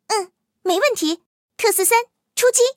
T43出击语音.OGG